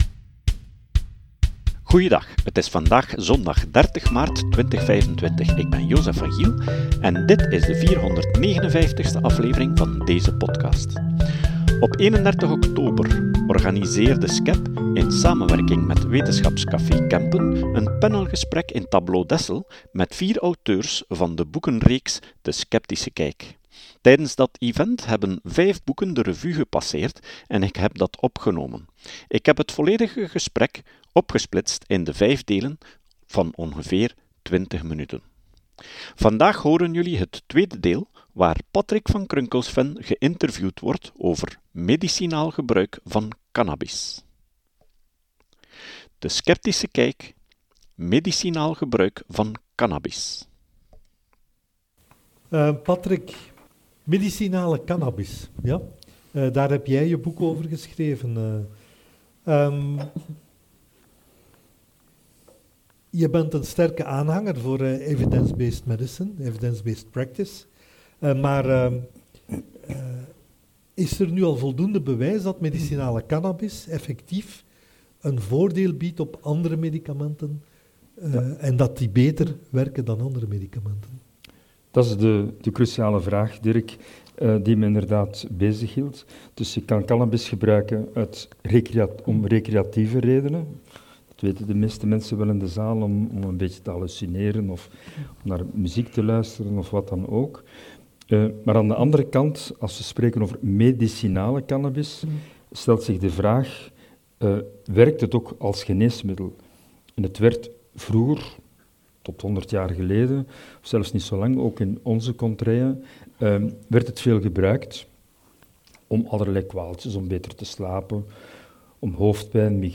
Tijdens dat event hebben vijf boeken de revue gepasseerd en ik heb dat opgenomen.